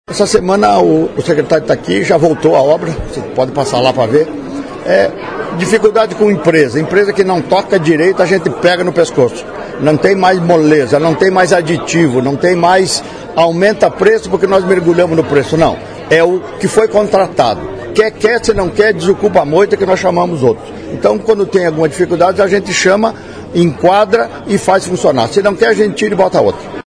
Durante a coletiva concedida pelo governador Jorginho Mello, nossa reportagem também perguntou sobre as obras da SC-451, entre Frei Rogério e Fraiburgo.